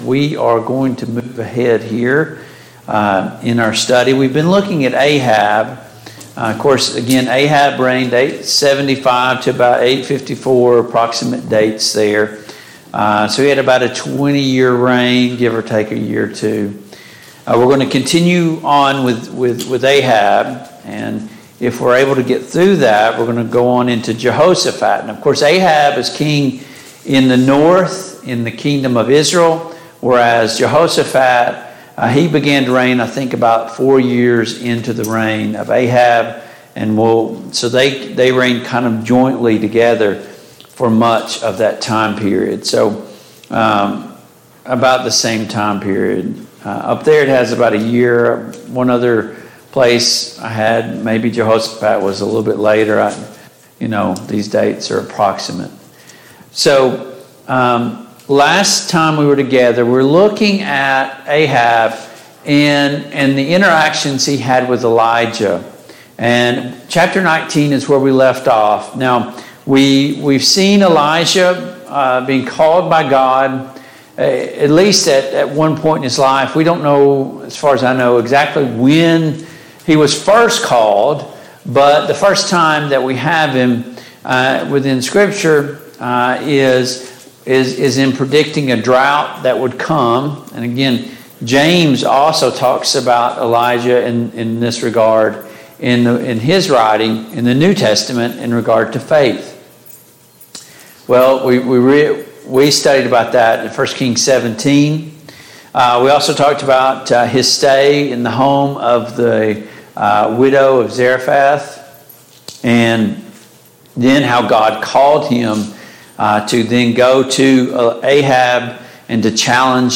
The Kings of Israel Passage: 1 Kings 19, 1 Kings 20 Service Type: Mid-Week Bible Study Download Files Notes « Many Members in One Body 5.